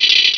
pokeemerald / sound / direct_sound_samples / cries / shuppet.aif
-Replaced the Gen. 1 to 3 cries with BW2 rips.